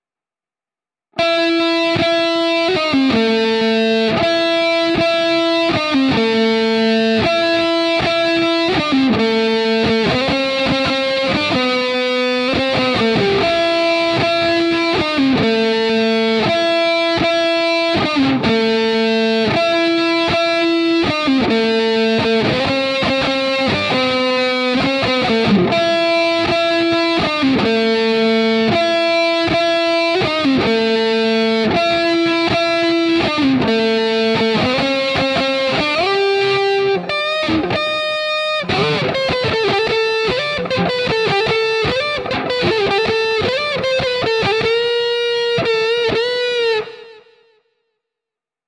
Финальное соло
Обработка через GuitarRIG 4